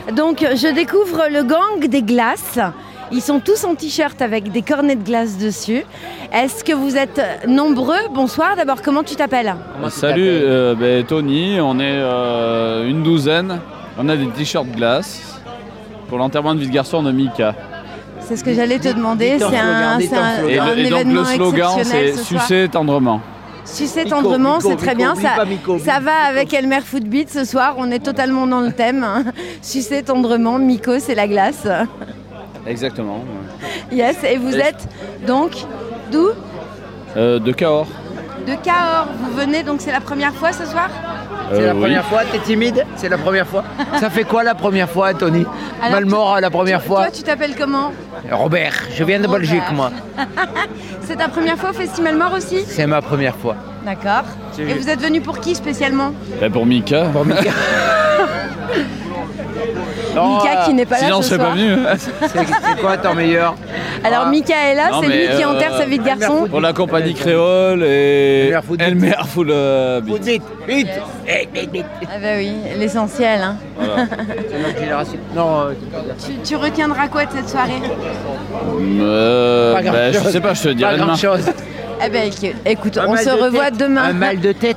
Interviews Festi'malemort